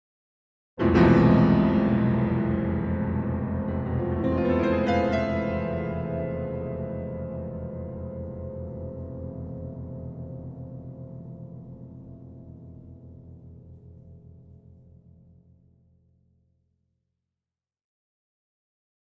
Piano Danger Chord Type B - Danger Melody In The End